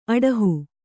♪ aḍahu